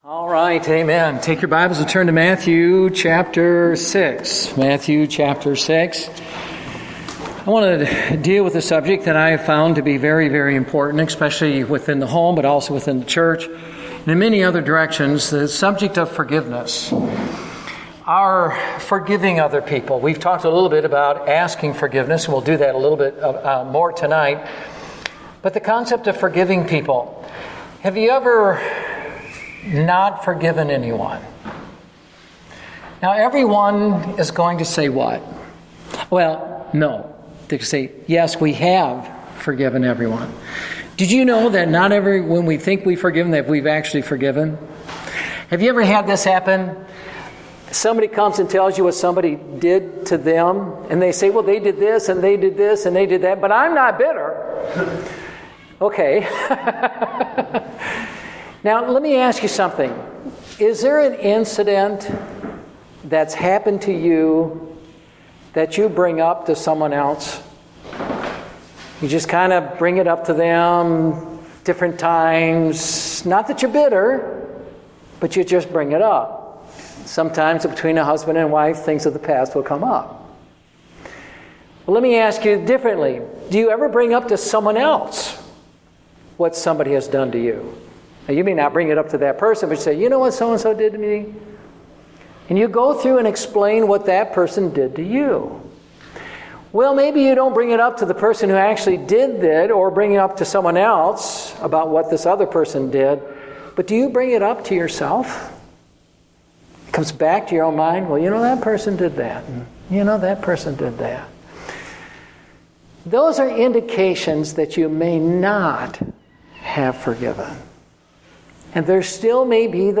Date: August 22, 2014 (Family Camp)